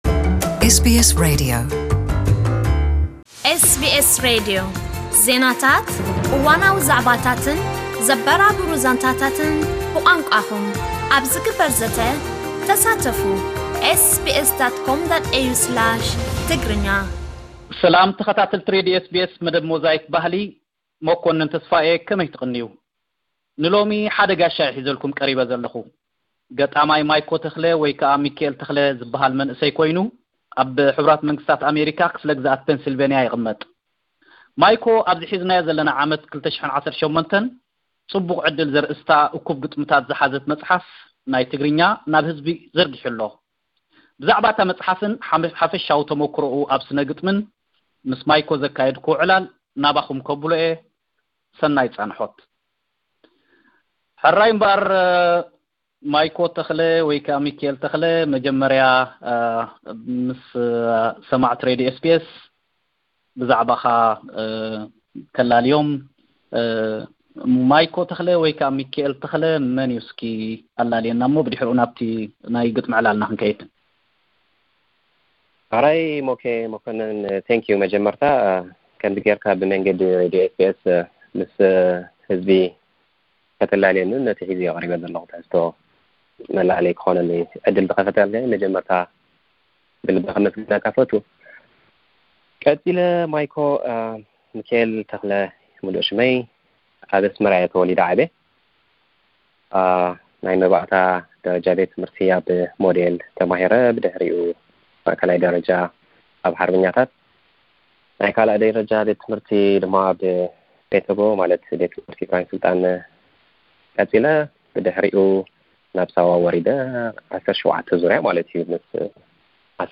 ዕላላ